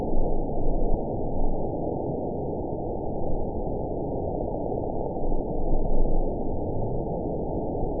event 919854 date 01/26/24 time 02:28:52 GMT (1 year, 3 months ago) score 8.89 location TSS-AB07 detected by nrw target species NRW annotations +NRW Spectrogram: Frequency (kHz) vs. Time (s) audio not available .wav